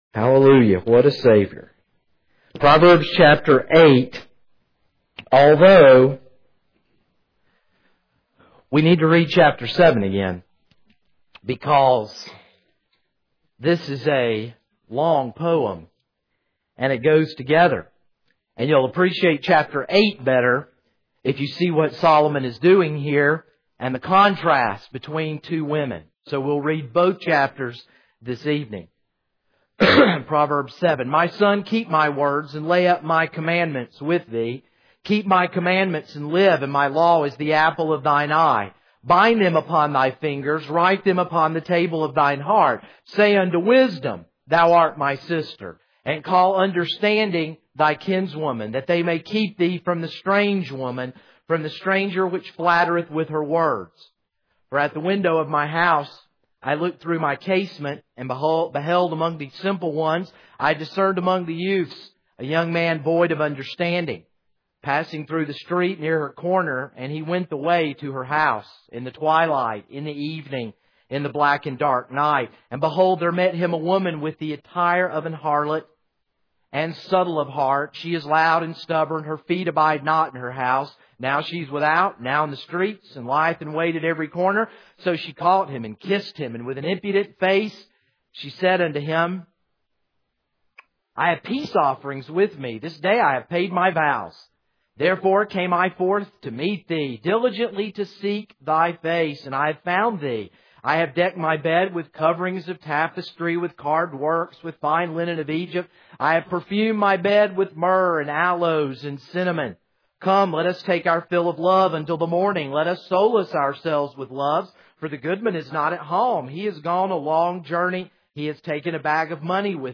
This is a sermon on Proverbs 8.